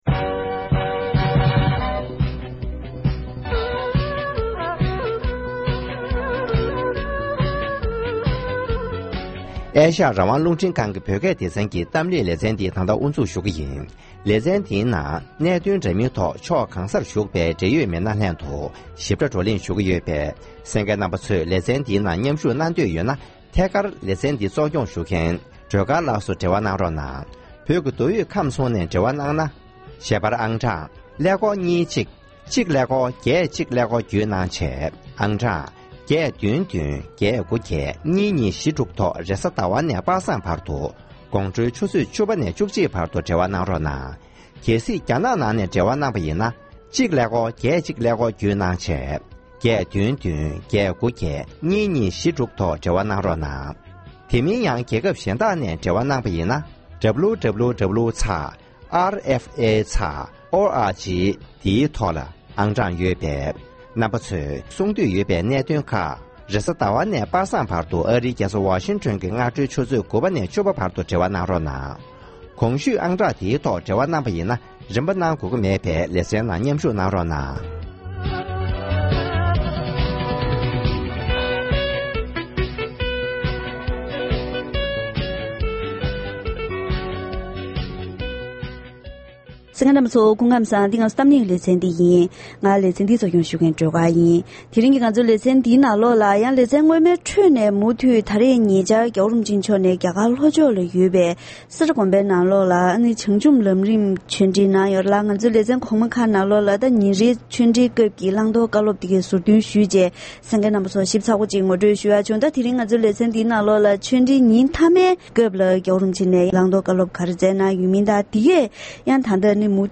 ༄༅༎དེ་རིང་གི་གཏམ་གླེང་ལེ་ཚན་ནང་ཉེ་ཆར་རྒྱ་གར་ལྷོ་ཕྱོགས་སེ་ར་དགོན་པར་བྱང་ཆུབ་ལམ་རིམ་གྱི་གསུང་ཆོས་ཉིན་མཐའ་མའི་སྐབས་སྤྱི་ནོར་༸གོང་ས་༸སྐྱབས་མགོན་ཆེན་པོ་མཆོག་ནས་བོད་ནང་གི་བོད་མིའི་སྙིང་སྟོབས་ལ་བསྔགས་བརྗོད་གནང་བ་དང་དུས་མཚུངས་བོད་མི་ཚོས་བོད་སྐད་ཡིག་དང་རིག་གཞུང་། བོད་ཀྱི་ནང་ཆོས་ལ་དོ་སྣང་གཅེས་འཛིན་དགོས་པ་དང་། དབུ་མའི་ལམ་གྱི་སྲིད་ཇུས་ལག་བསྟར་གནང་གི་ཡོད་སྐོར་སོགས་བཀའ་སློབ་གནང་བ་ངོ་སྤྲོད་དང་། དེ་བཞིན་གསུང་ཆོས་གོ་སྒྲིག་ལྷན་ཚོགས་ལ་འབྲེལ་ཡོད་གནས་ཚུལ་བཀའ་འདྲི་ཞུས་པ་ཞིག་གསན་རོགས་གནང་།།